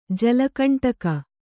♪ jala kaṇṭaka